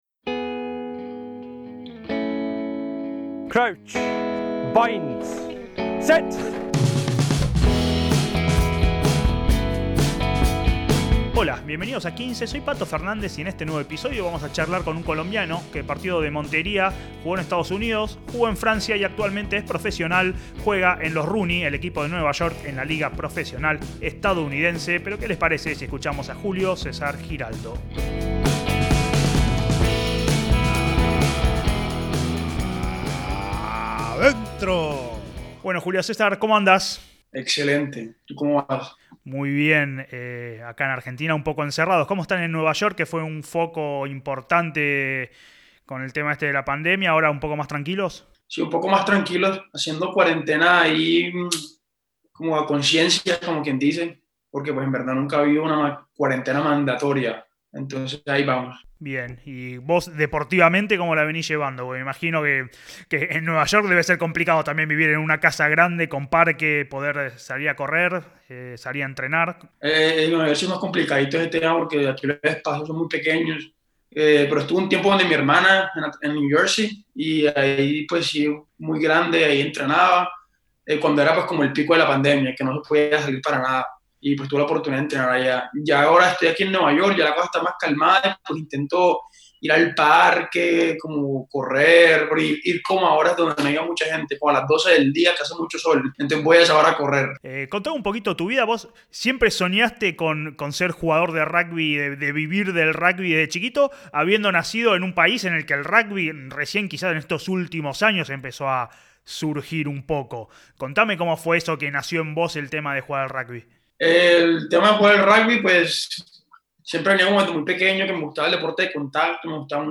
¡Charlas de rugby con los protagonistas!